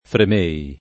fremere [fr$mere] v.; fremo [fr$mo] — pass. rem. fremetti [frem$tti] o fremei [